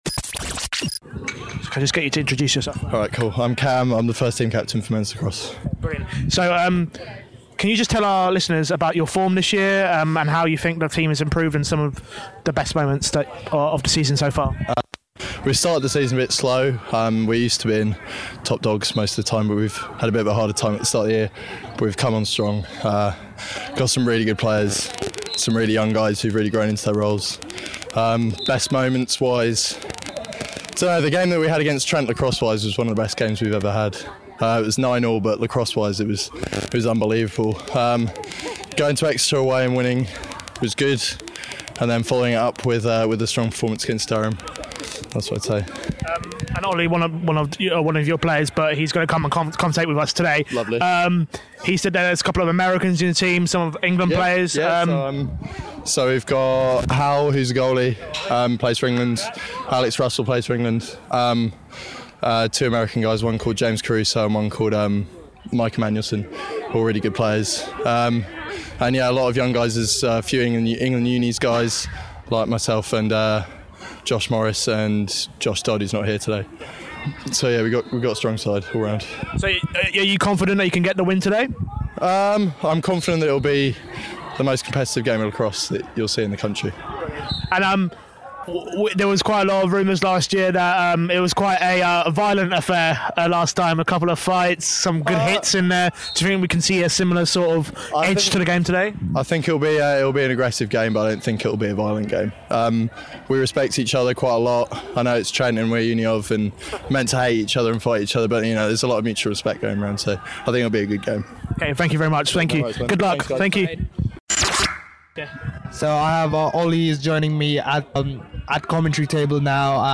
Varsity 2017 - Lacrosse pre-match interviews